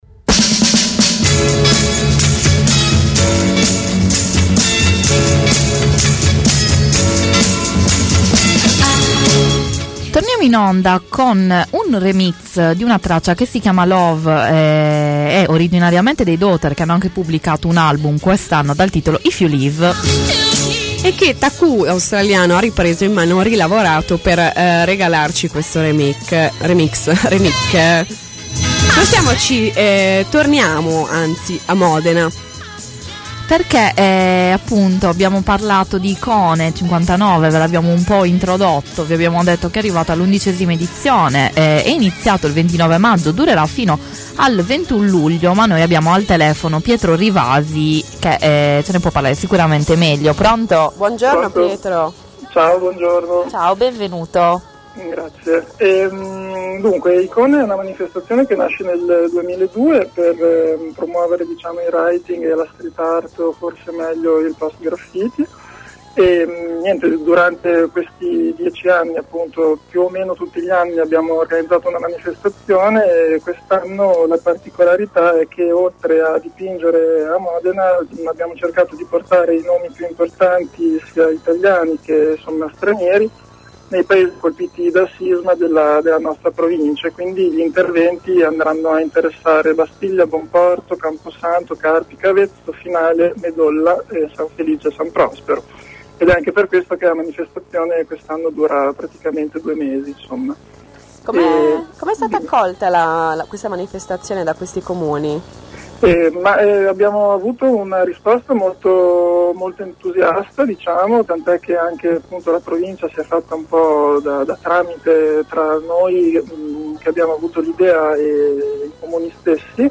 intervista-icone59.mp3